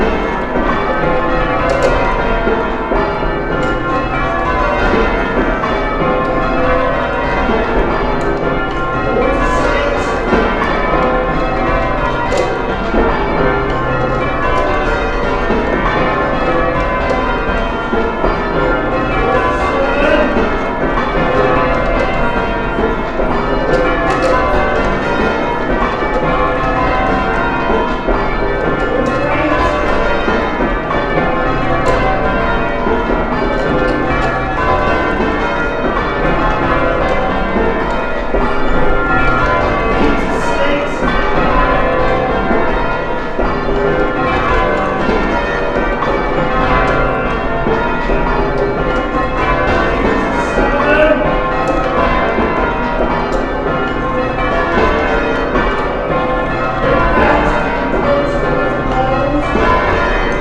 Each bell strike depends on timing, teamwork, and precision.
Church-bells.wav